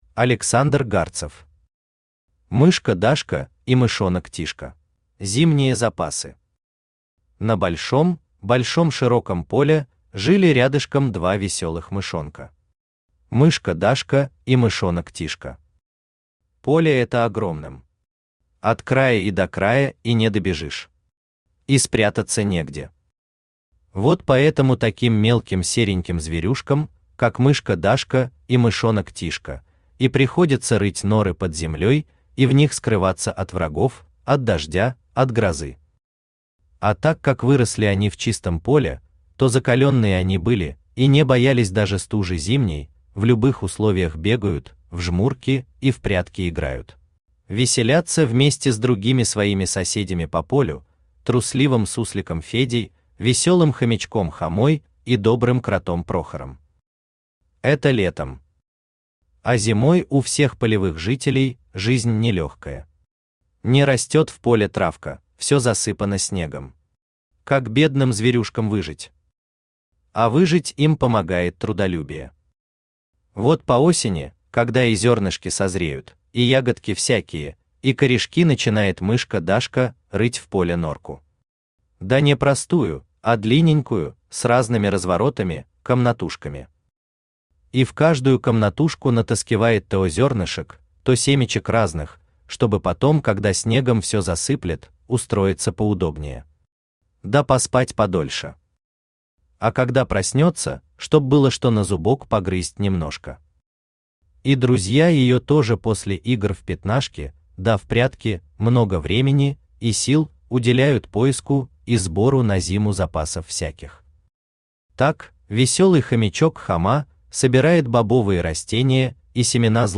Аудиокнига Мышка Дашка и мышонок Тишка | Библиотека аудиокниг
Aудиокнига Мышка Дашка и мышонок Тишка Автор Александр Гарцев Читает аудиокнигу Авточтец ЛитРес.